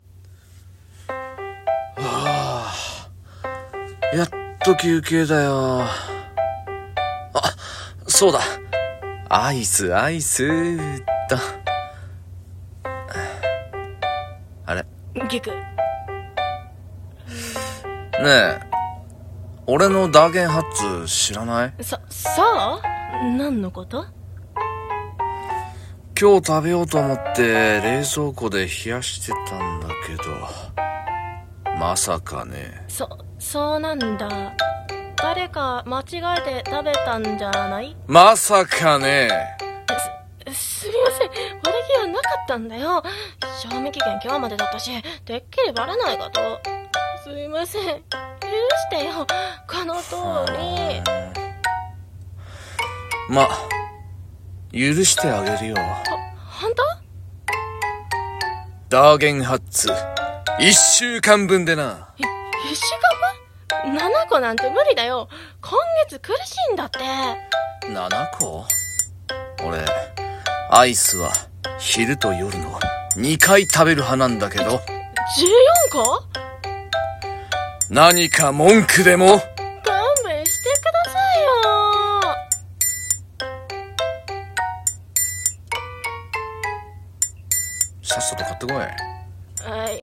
【ギャグ声劇台本】アイス